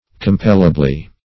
compellably - definition of compellably - synonyms, pronunciation, spelling from Free Dictionary Search Result for " compellably" : The Collaborative International Dictionary of English v.0.48: Compellably \Com*pel"la*bly\, adv.